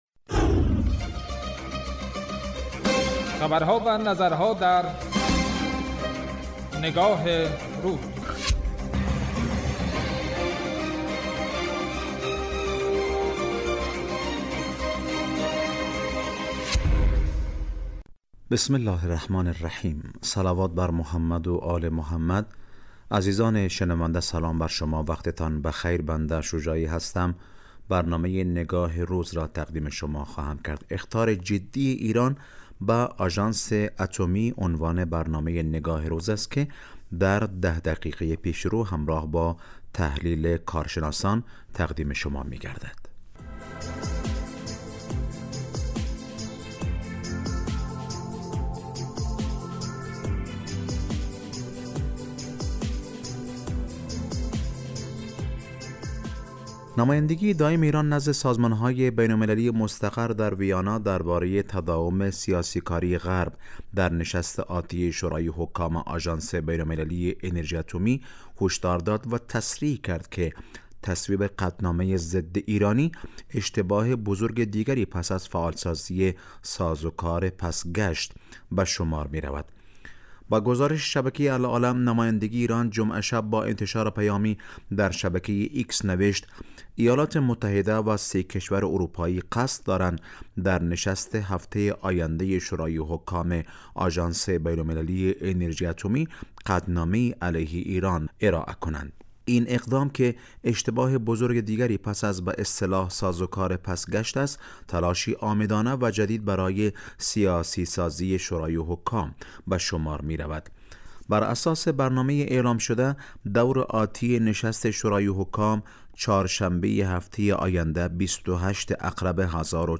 برنامه نگاه روز به بررسی موضوعات سیاسی، فرهنگی، اجتماعی و اقتصادی ایران، افغانستان و سایر نقاط جهان می پردازد که به مدت ده دقیقه از شنبه تا پنجشنبه ساعت 2 بعد از ظهر به وقت افغانستان از رادیو دری پخش می شود.